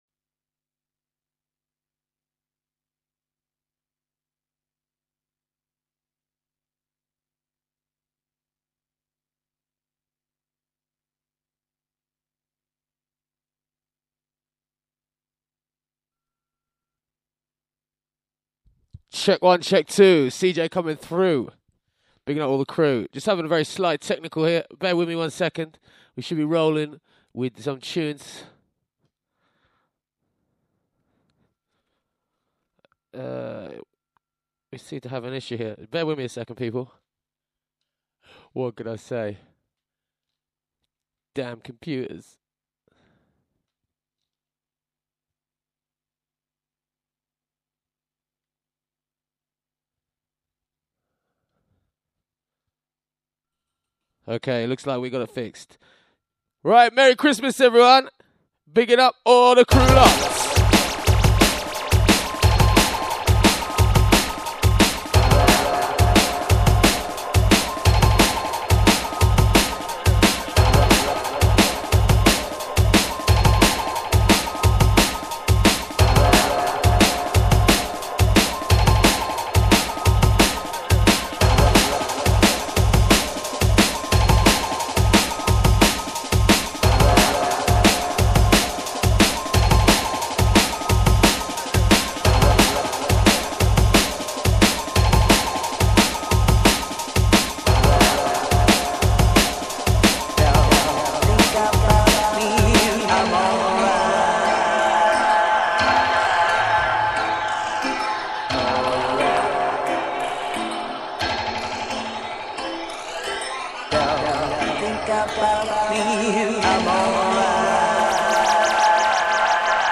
The OVERLOAD Show live from Midlands, UK.mp3